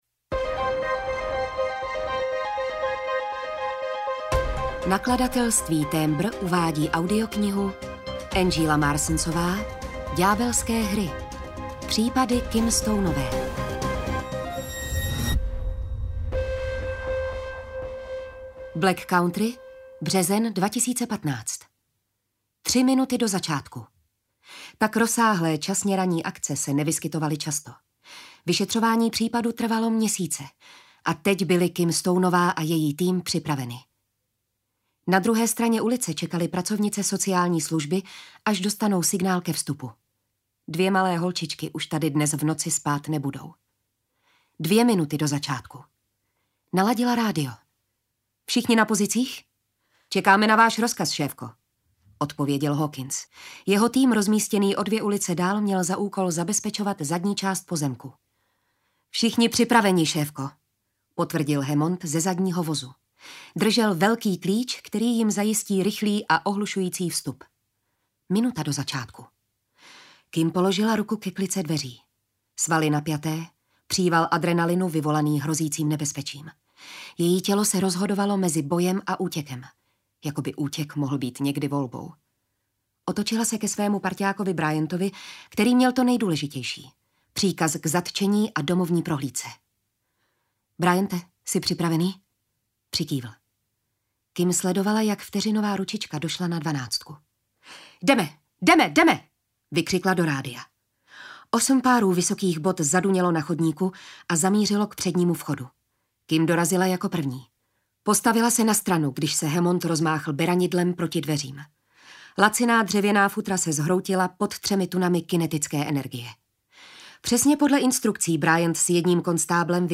Ďábelské hry audiokniha
Ukázka z knihy
• InterpretJitka Ježková